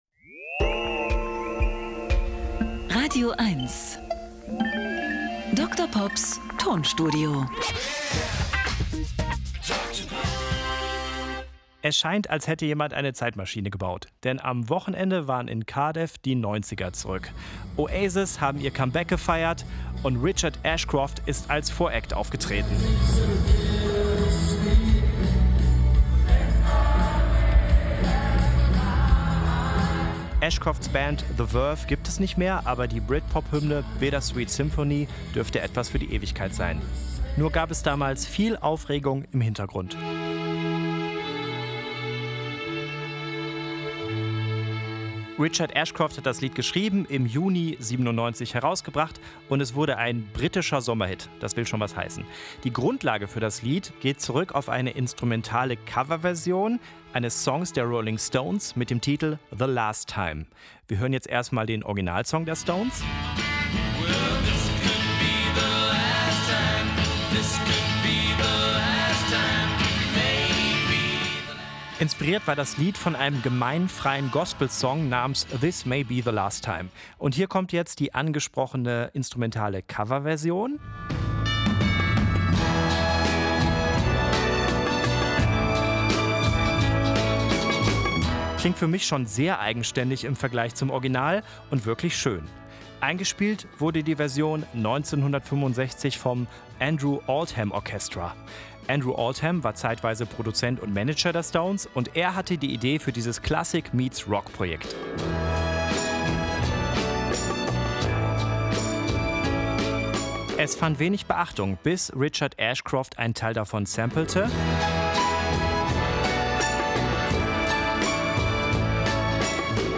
Er therapiert mit Musiksamples und kuriosen, aber völlig wahren Musikfakten.
Comedy